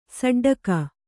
♪ saḍḍaka